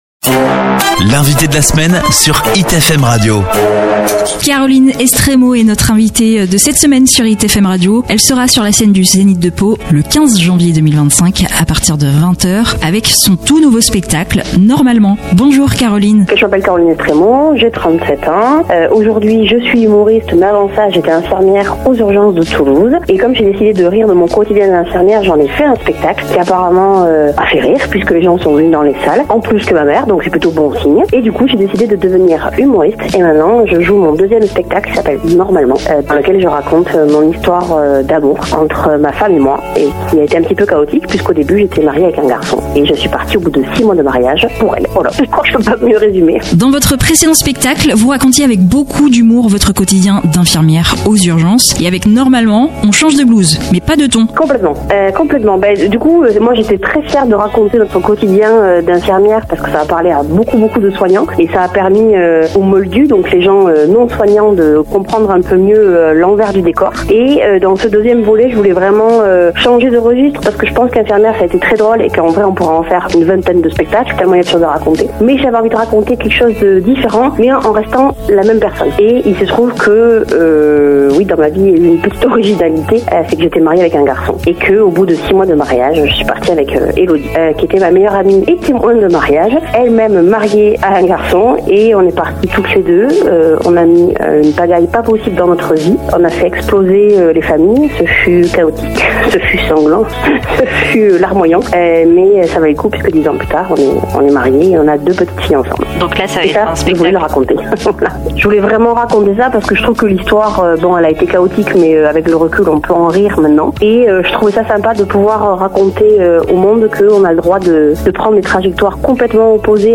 Au micro de Hit FM radio, l’humoriste revient sur un spectacle plus personnel, où elle aborde la famille, le couple, et même Céline Dion, toujours avec son franc-parler jubilatoire.